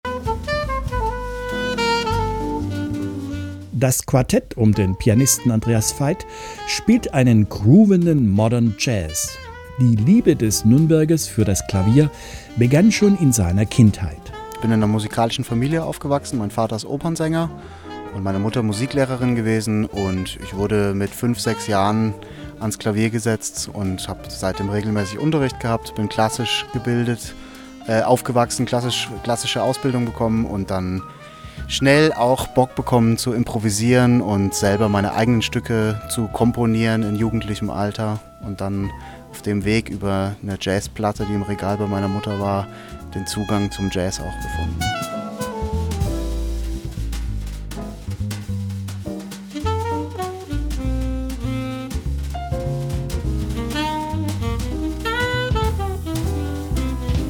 groovenden Modern Jazz
Piano
Altsaxofon
Kontrabass
Schlagzeug